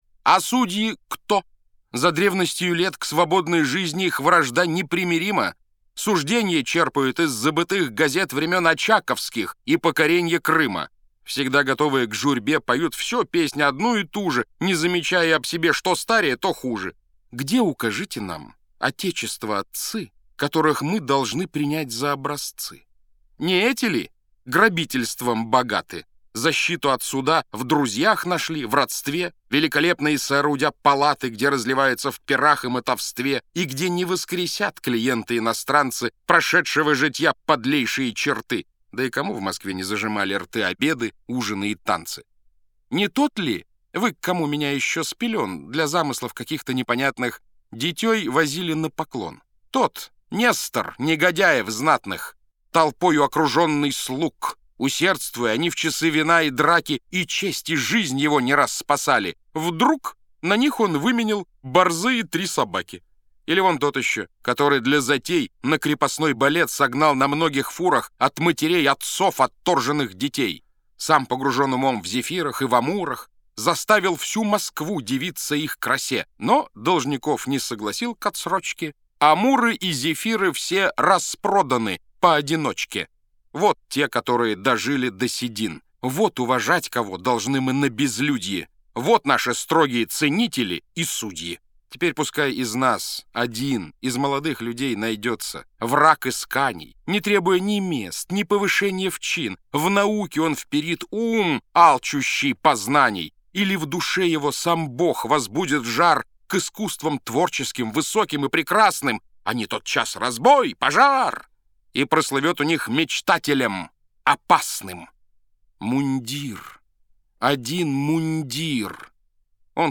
Монолог Чацкого